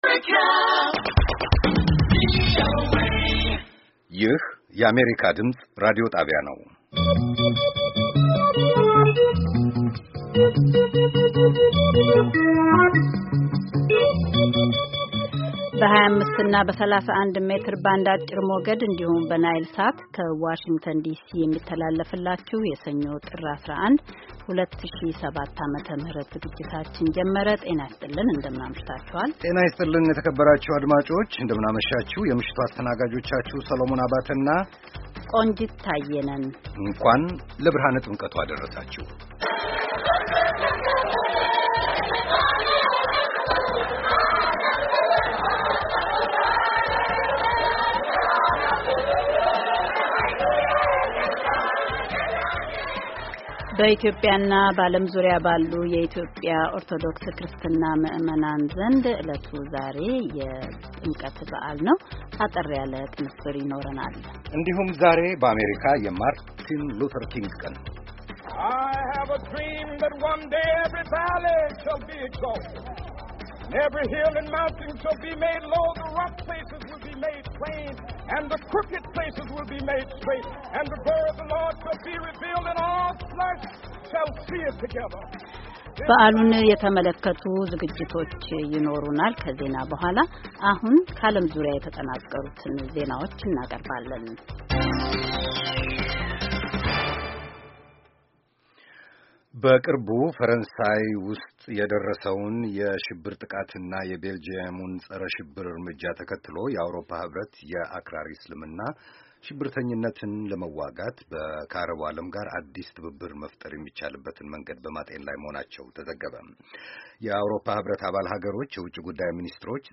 ከምሽቱ ሦስት ሰዓት የአማርኛ ዜና
ቪኦኤ በየዕለቱ ከምሽቱ 3 ሰዓት ጀምሮ በአማርኛ፣ በአጭር ሞገድ 22፣ 25 እና 31 ሜትር ባንድ ከሚያሠራጨው የ60 ደቂቃ ዜና፣ አበይት ዜናዎች ትንታኔና ሌሎችም ወቅታዊ መረጃዎችን የያዙ ፕሮግራሞች በተጨማሪ ከሰኞ እስከ ዐርብ ከምሽቱ 1 ሰዓት እስከ 1 ሰዓት ተኩል በአማርኛ የሚተላለፍ የግማሽ ሰዓት ሥርጭት በ1431 መካከለኛ ሞገድ ላይ አለው፡፡